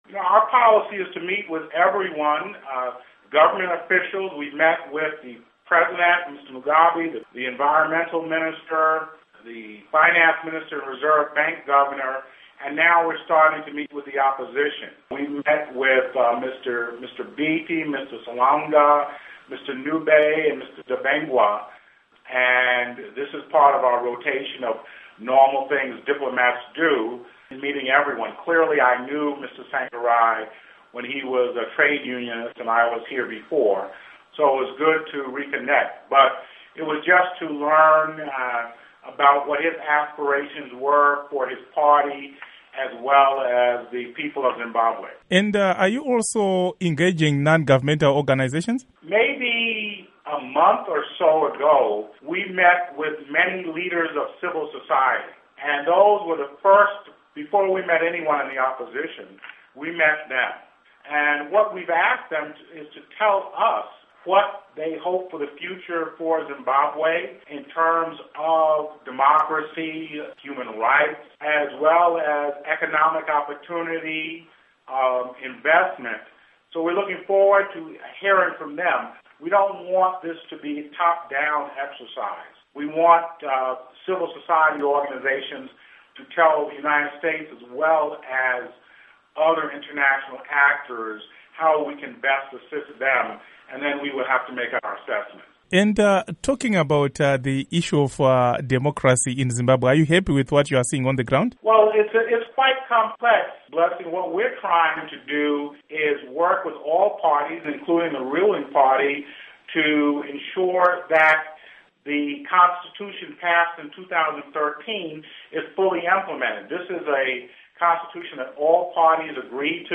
Interview With US Ambassador Harry Thomas Jnr. on Zimbabwe